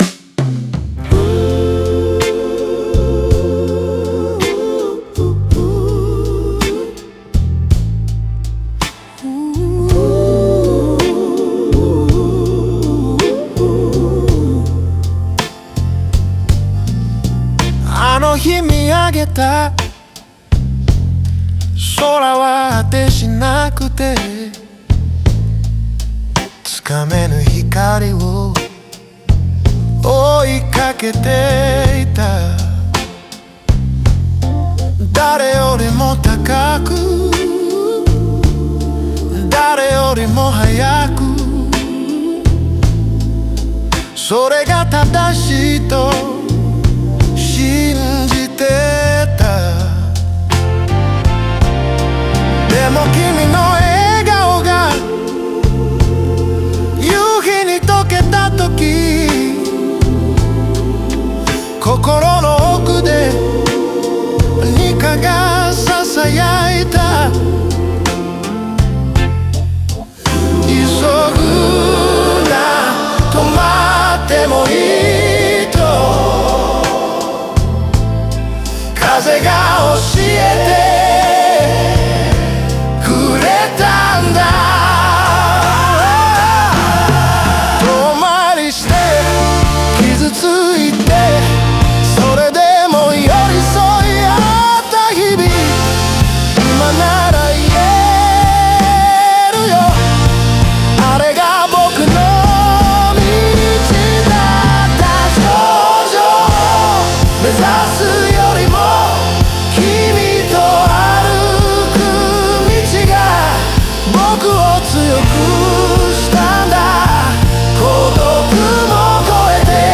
スライドギターが空を描くように鳴り、
ホーンが呼応し、ドラムがスネアで光を刻む。
クワイアが「Ah〜」と祈るように響く。
全体の空気が高揚し、ステージが黄金色に包まれる。